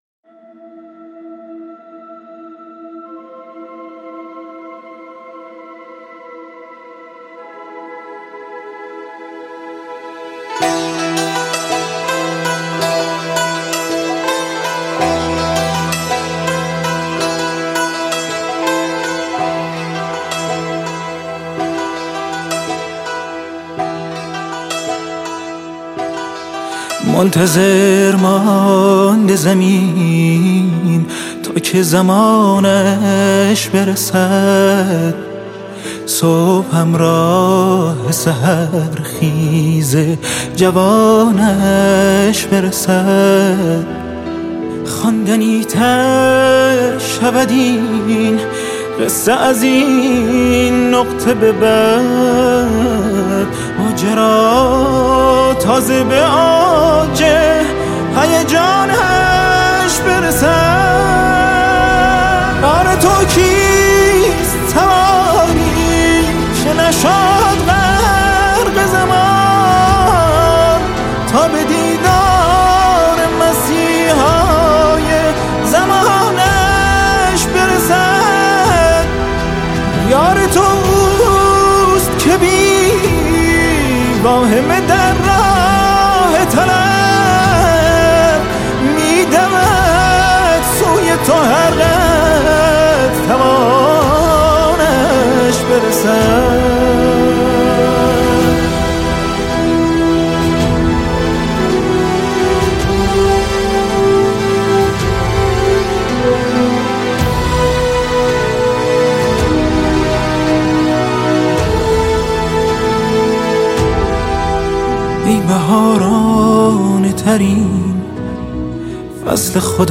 نماهنگ مهدوی